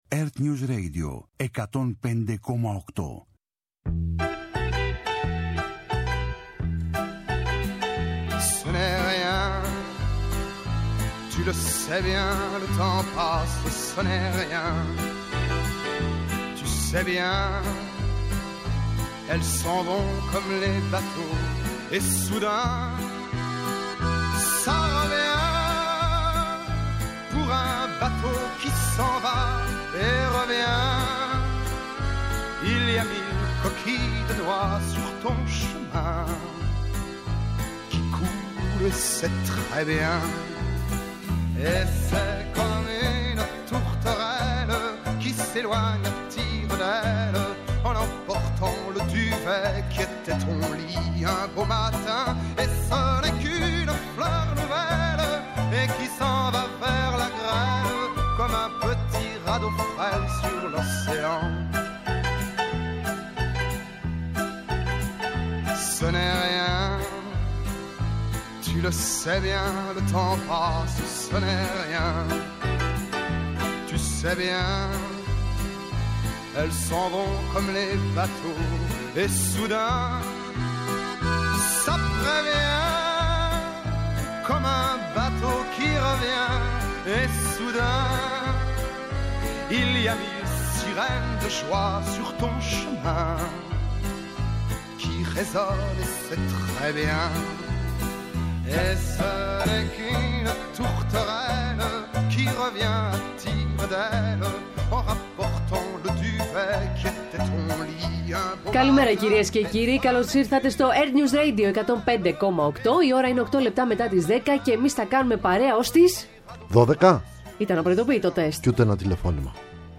-Ο Σπύρος Πρωτοψάλτης, γενικός γραμματέας αγορτικής ανάπτυξης, για την ευλογιά των προβάτων
Συζήτηση με τους πρωταγωνιστές των γεγονότων. Ανάλυση των εξελίξεων στην Ελλάδα και σε όλο τον πλανήτη και αποκωδικοποίηση της σημασίας τους. ΕΡΤNEWS RADIO